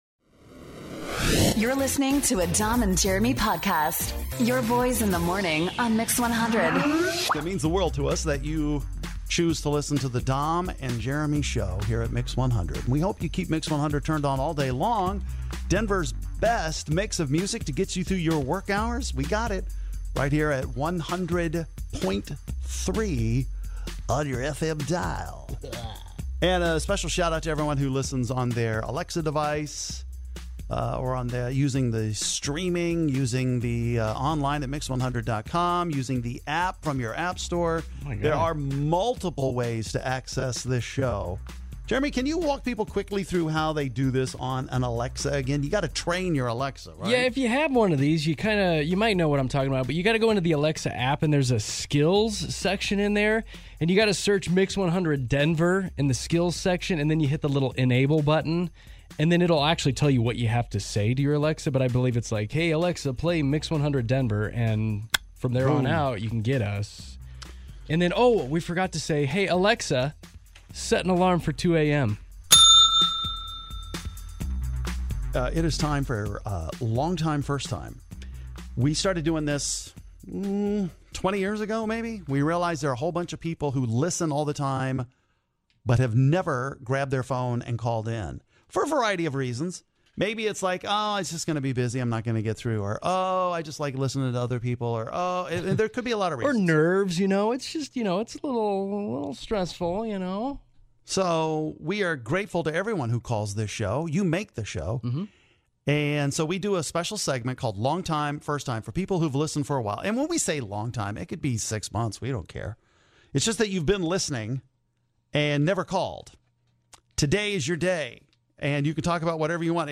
We had long time listeners call in today for the first time and had some great conversations with them.